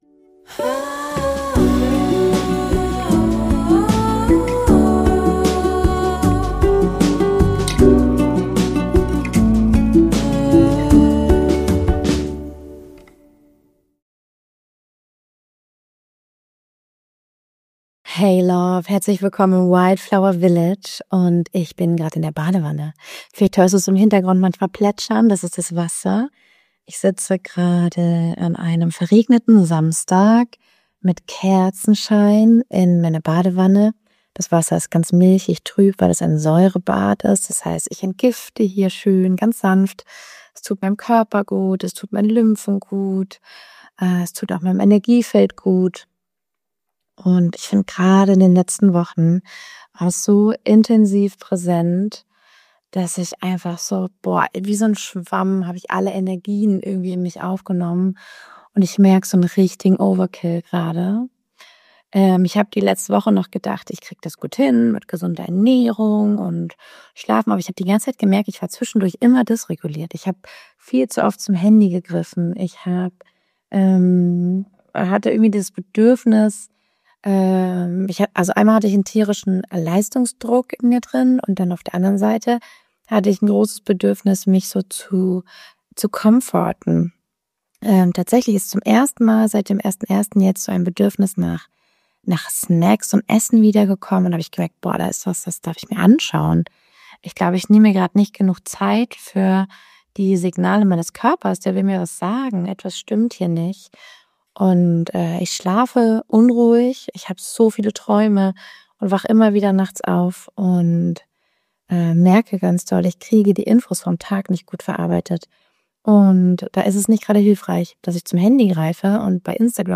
In dieser Folge teile ich direkt aus der Badewanne heraus einen ehrlichen Moment der Stagnation: Warum uns das Internet buchstäblich aus dem Körper saugen kann und w...